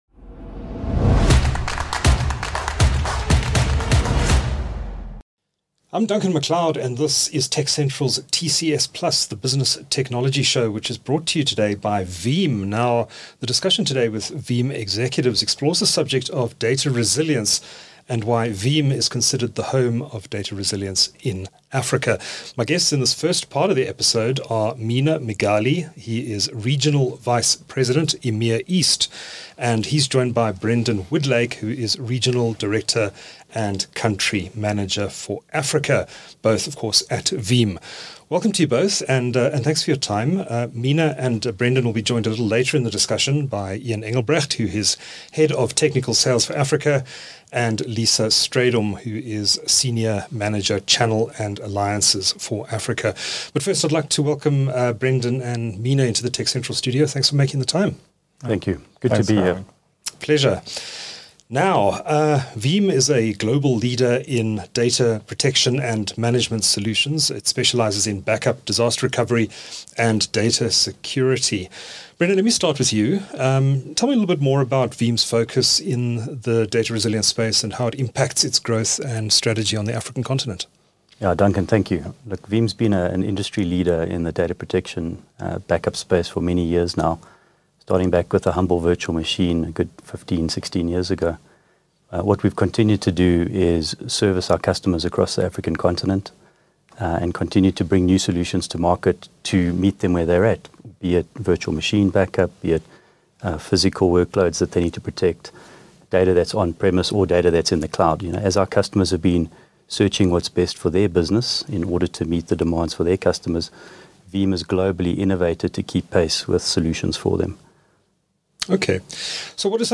TechCentral's TCS+ is a business technology show that brings you interviews with leaders in South Africa's technology industry - and further afield. It showcases the latest products and services available to businesses large and small.